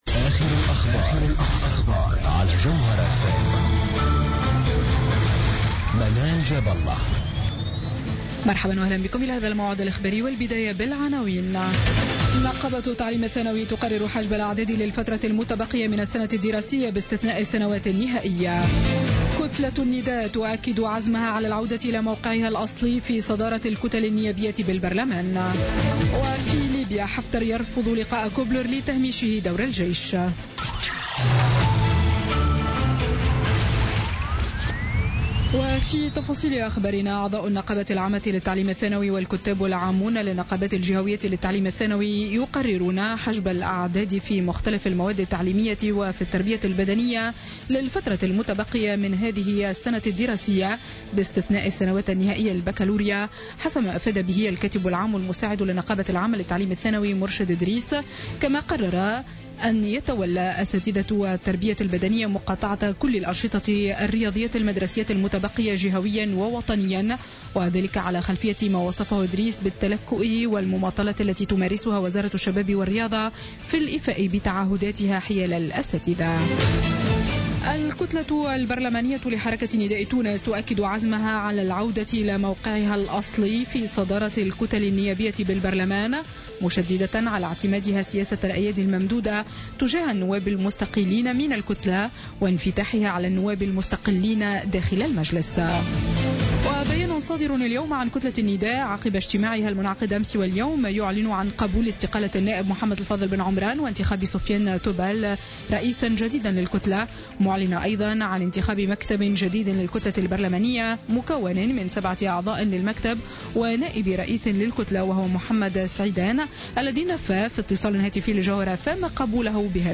Journal Info 19h00 du dimanche 8 Mai 2016